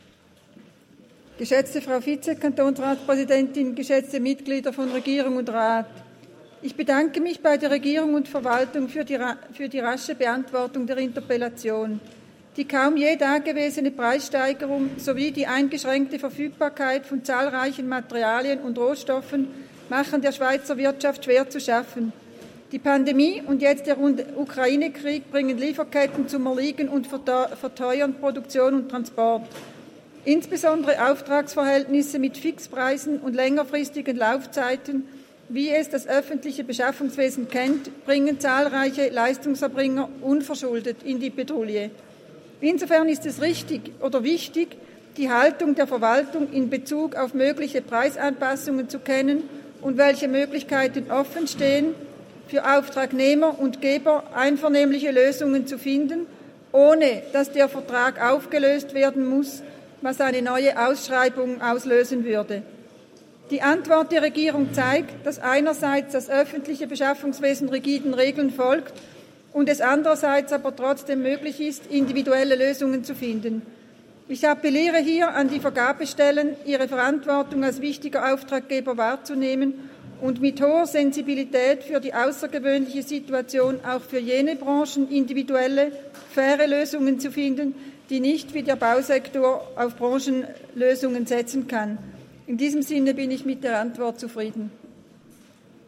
Geschäft des Kantonsrates St.Gallen: Steigende Materialkosten – wie geht der Kanton als wichtiger Auftraggeber damit um?